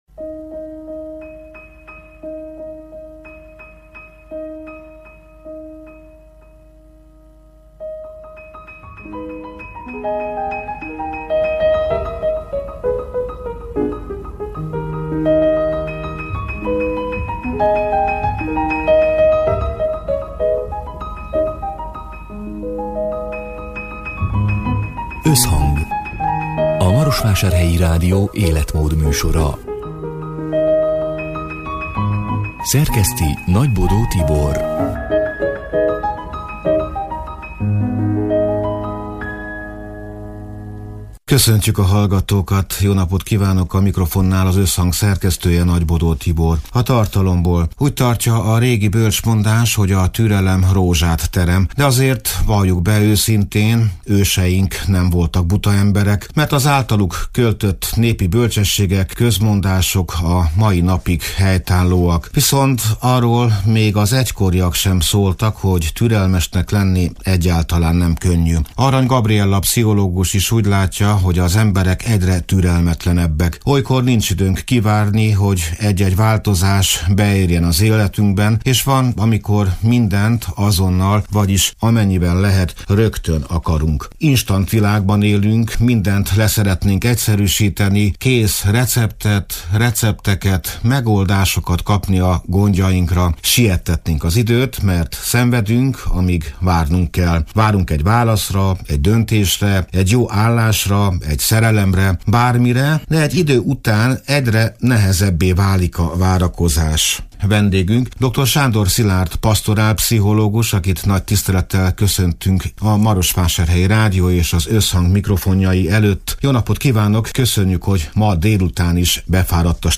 A Marosvásárhelyi Rádió Összhang (elhangzott: 2026. január 14-én, szerdán délután hat órától) c. műsorának hanganyaga: A türelemről azt tanultuk, hogy rózsát terem. Azonban kevesen beszélnek arról, hogy milyen nehéz elviselni a várakozás perceit amikor siettetnénk az időt, mert fáj a bizonytalanság.